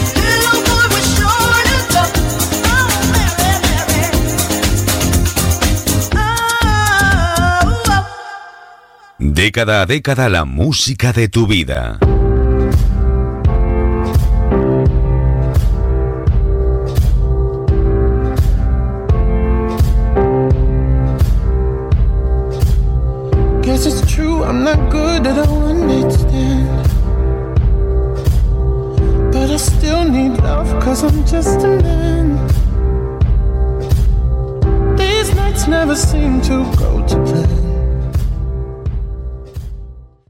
Temes musicals i eslògan.
Música, identificació de la ràdio i tema musical.
Musical